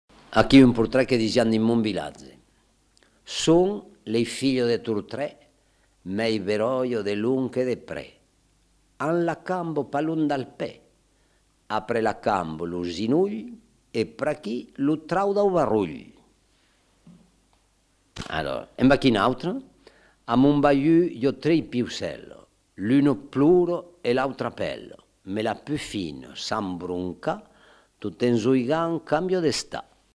Aire culturelle : Haut-Agenais
Lieu : Lauzun
Genre : forme brève
Type de voix : voix d'homme
Production du son : récité
Classification : blason populaire